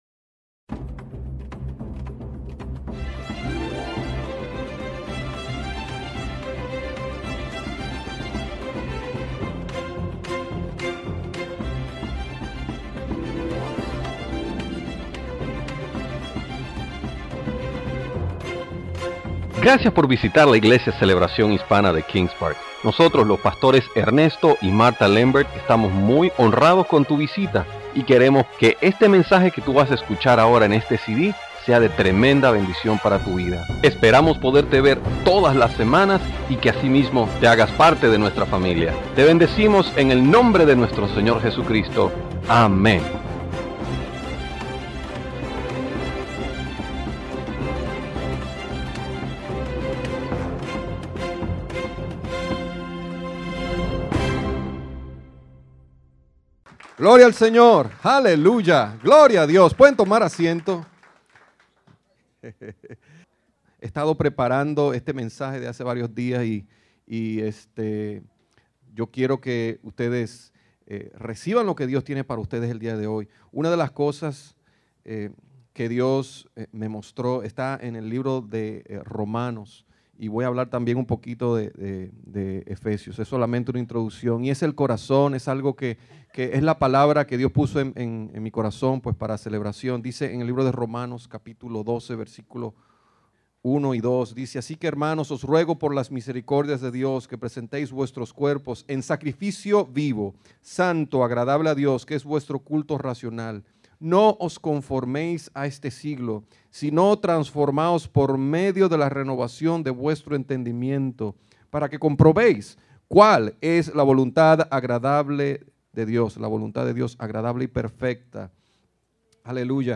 Series: Servicio Dominical